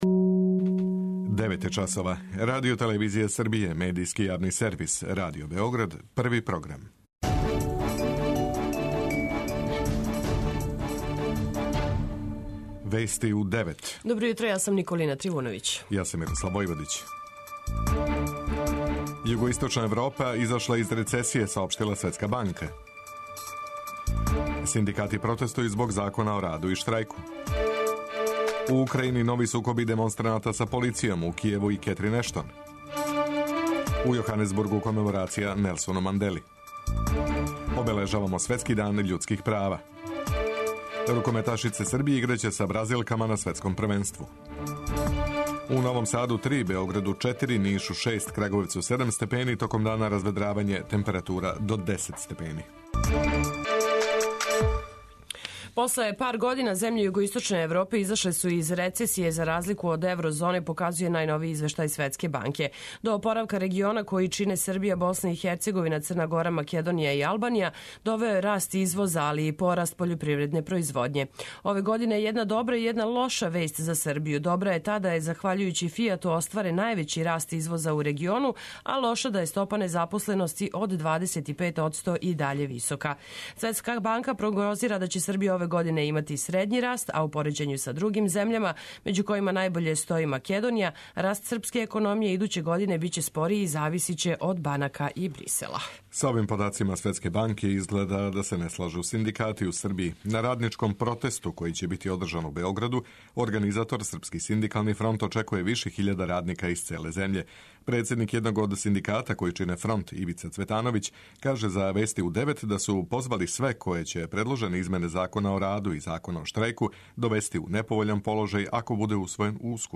Уредници и водитељи